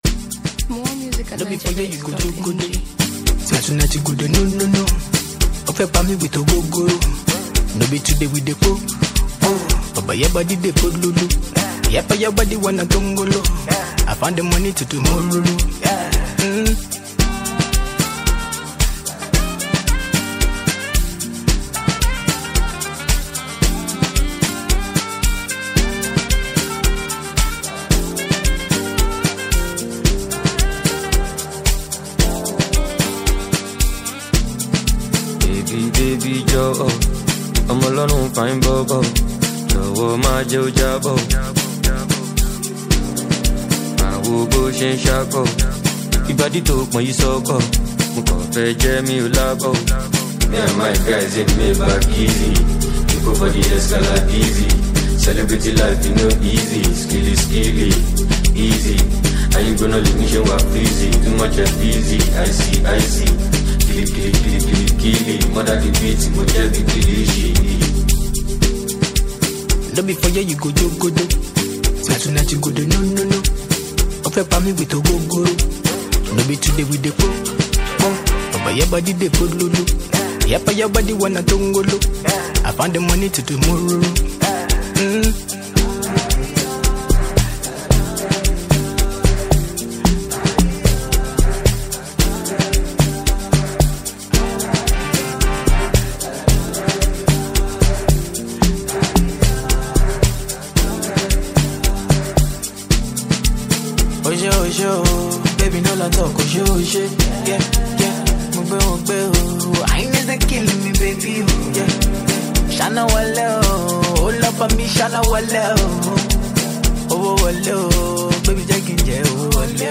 vibrant Afrobeats anthem